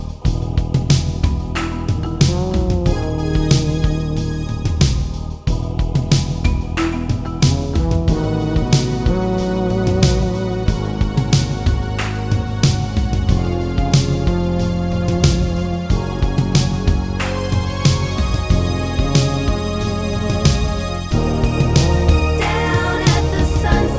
No Guitar Rock 4:42 Buy £1.50